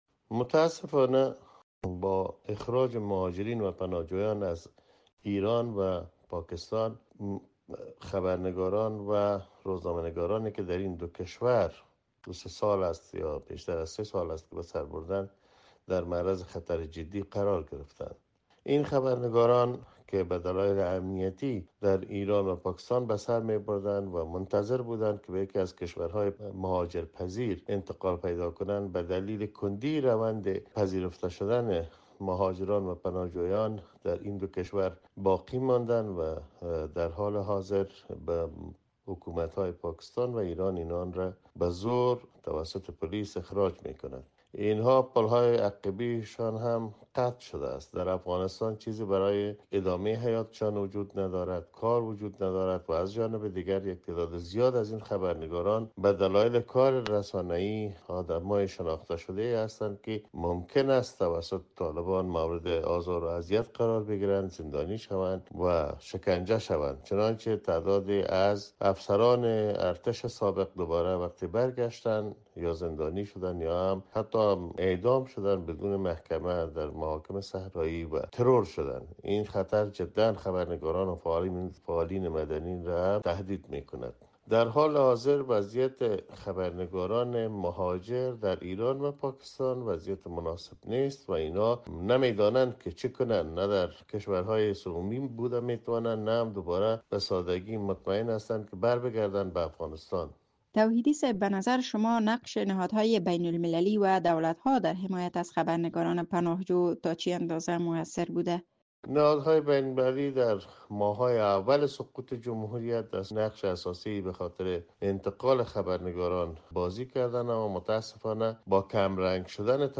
سرنوشت مبهم خبرنگاران افغان در ایران و پاکستان؛ گفت‌وگو